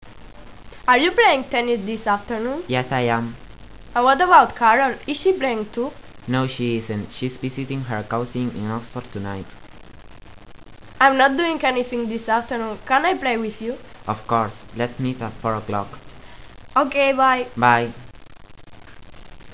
Chico y chica de pié en pista deportiva mantienen una conversación